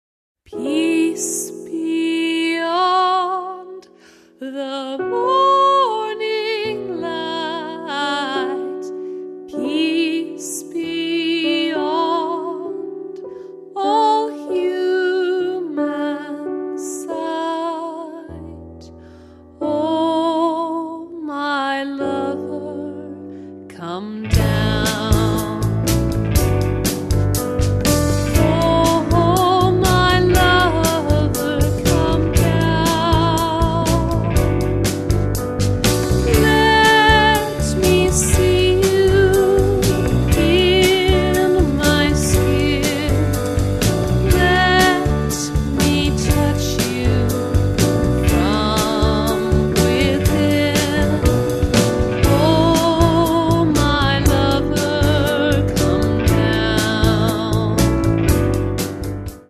piano
guitar
double bass
drums